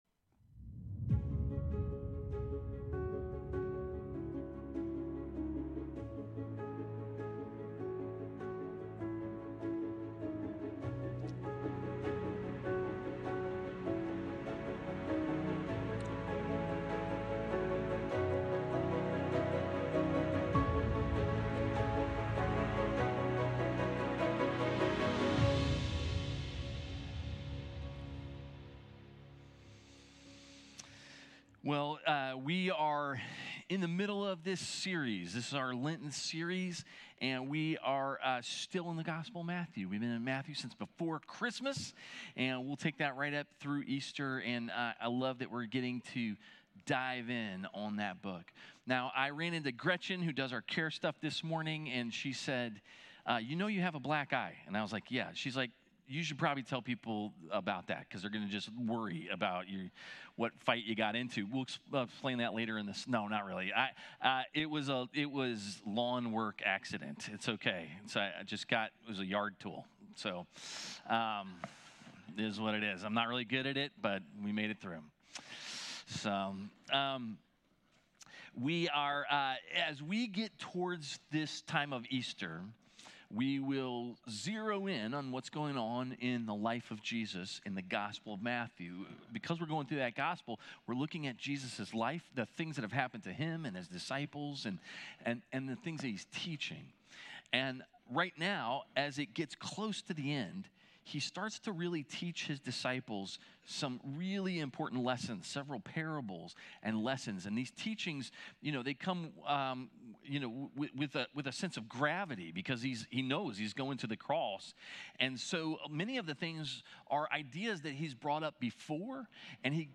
A message from the series "Kingdom Come." On the morning of the resurrection, both the women and the guards experience the same thing and were afraid but only the guards froze while the women had hope and left afraid but with great joy.